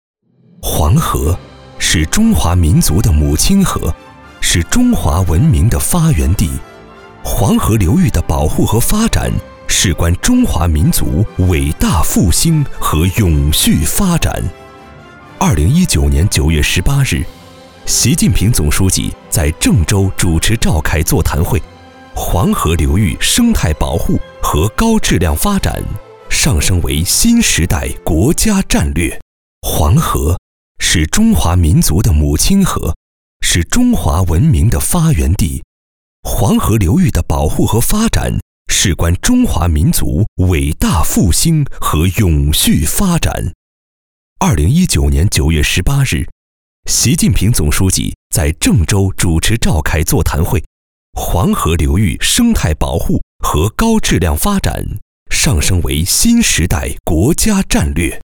男2号配音师
专题片-男2-黄河.mp3